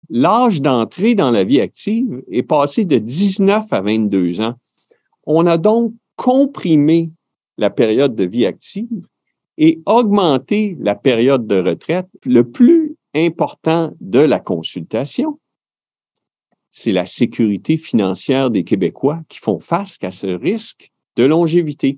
Le ministre des Finances, Éric Girard, s’est attardé à cet enjeu au lancement du Bilan de la fiscalité 2023 vendredi dernier.